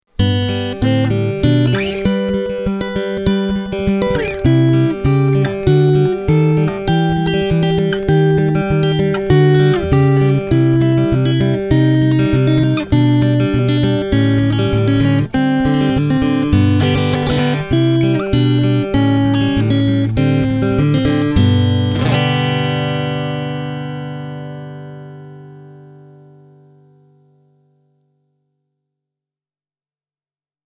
TW28-CLN:n akustinen ääni on lämmin, mutta silti selkeä.
Seymour Duncanin Woody kuulostaa erittäin hyvällä tavalla vanhanaikaiselta: basso on lämmin, keskialue täyteläinen, eikä diskantti liian tunkeileva tai pureva.
Tällaisia soundeja sain äänitettyä AKG C3000 -mikrofonilla, sekä Woodylla suoraan äänikortin kitaratuloon soittettuna:
Woody – näppäily
woody-e28093-fingerstyle.mp3